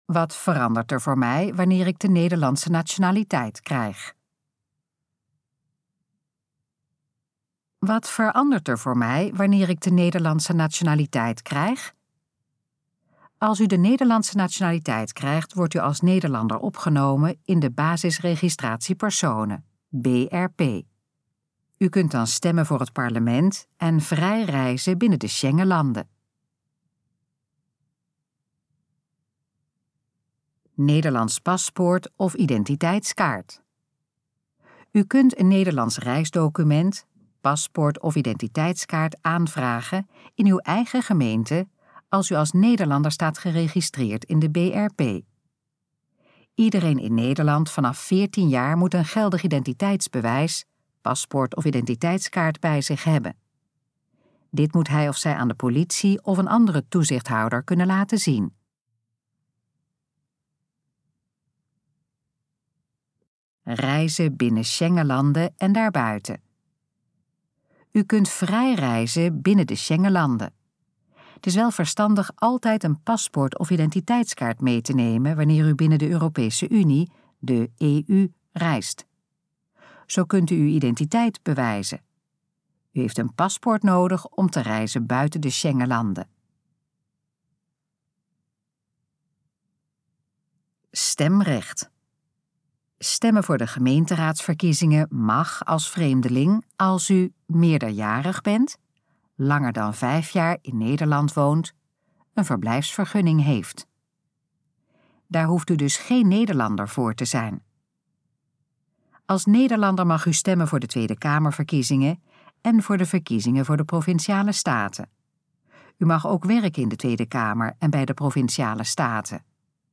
Gesproken versie van: Wat verandert er voor mij wanneer ik de Nederlandse nationaliteit krijg?
Dit geluidsfragment is de gesproken versie van de pagina: Wat verandert er voor mij wanneer ik de Nederlandse nationaliteit krijg?